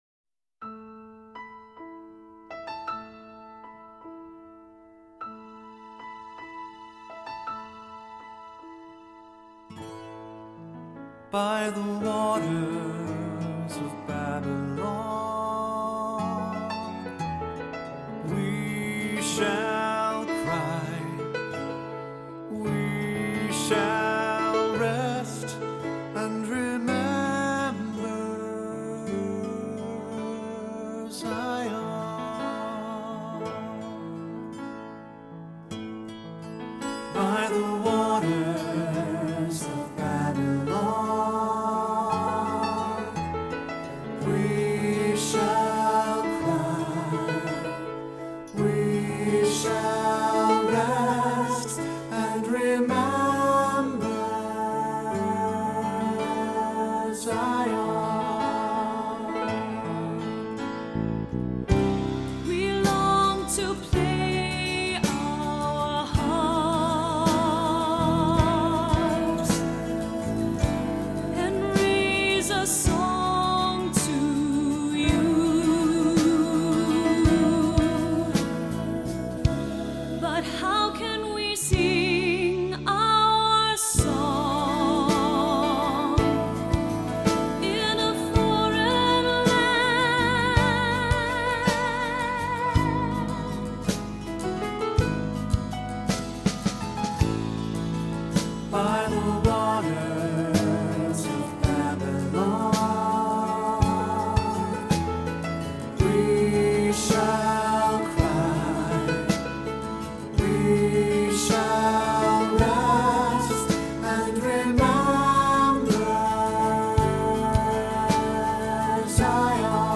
Voicing: Cantor, assembly